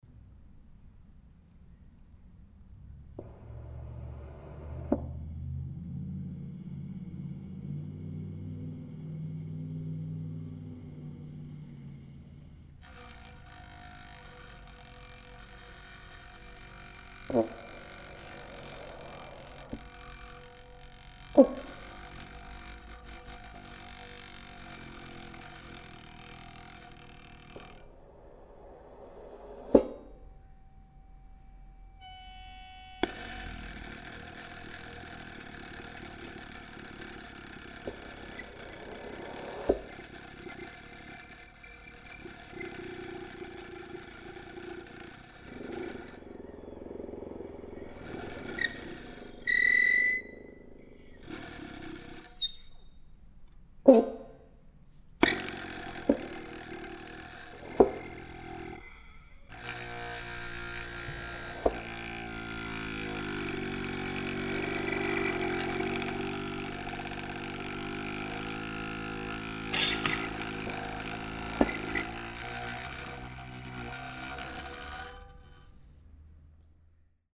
trumpet
electronics
alto saxophone
recorded in London, April 2009